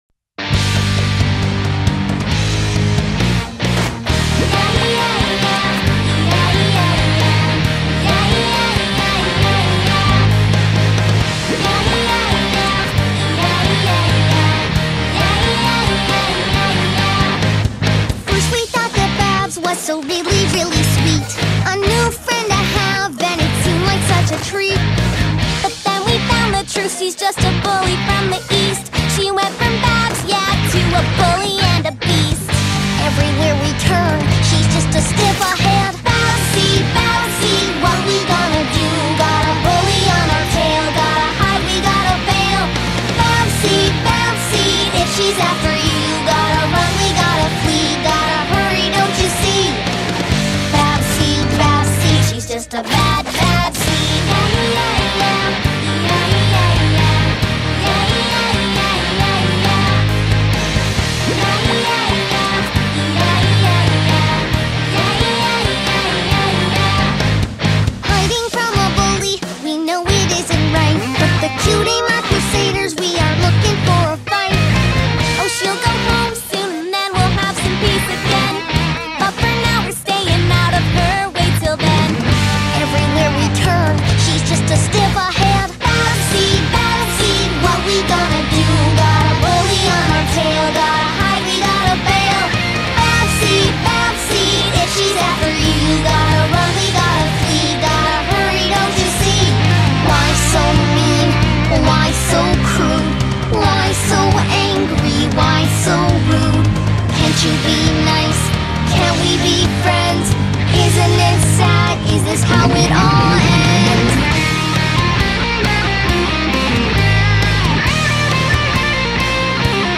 Rock Cover
Lead guitar http